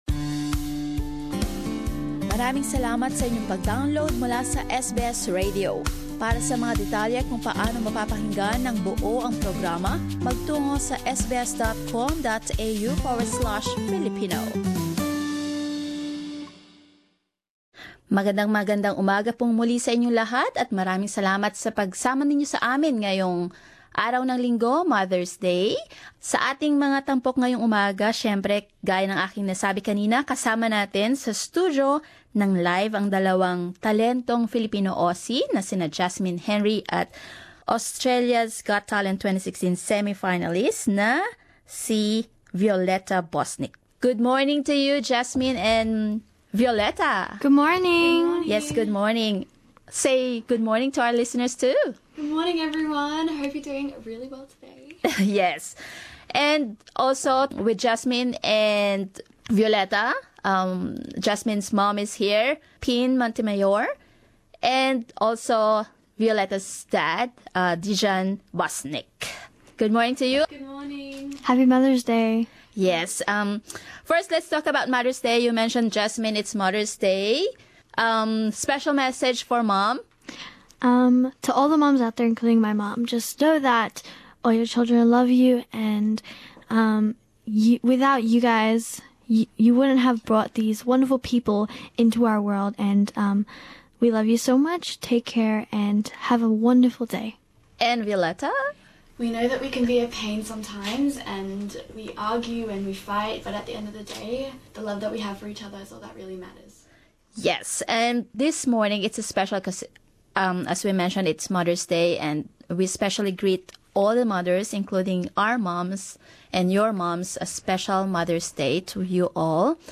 Singing their hearts out for all the mums, these two young talents share their message of love and gratitude to their mothers and all the mothers out there.